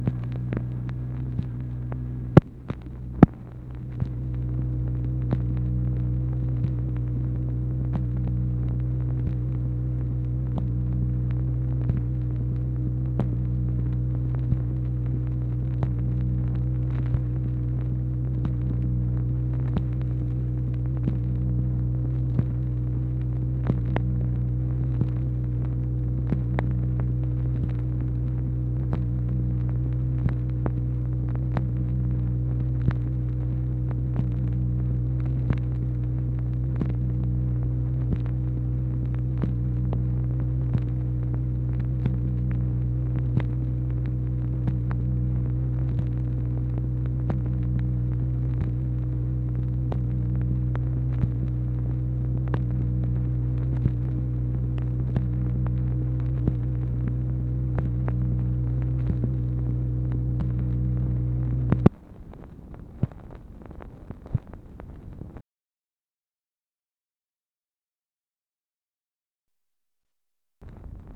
MACHINE NOISE, January 1, 1964
Secret White House Tapes | Lyndon B. Johnson Presidency